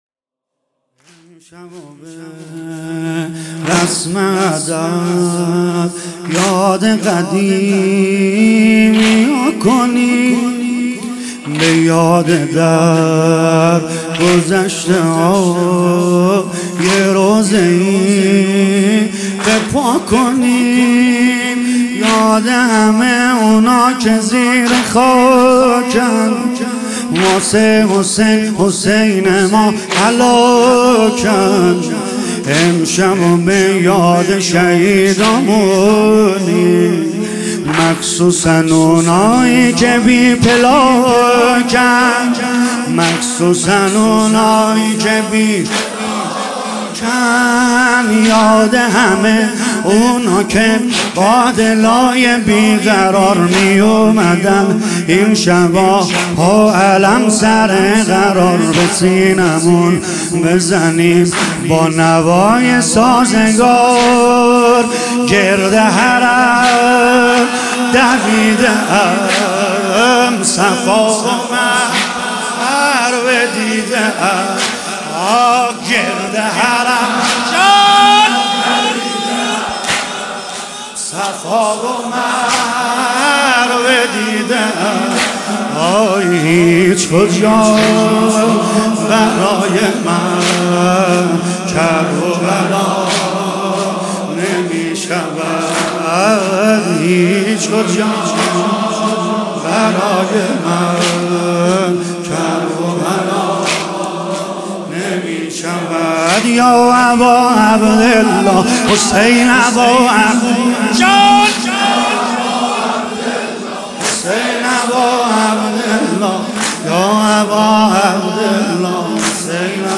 تولید شده: هیئت فدائیان حسین (ع) اصفهان